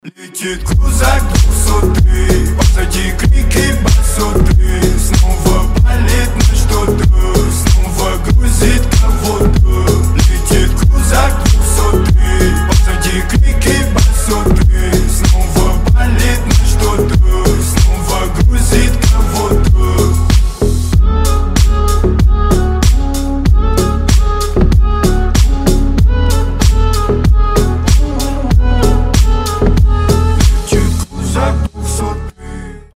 • Качество: 320, Stereo
басы
качающие
G-House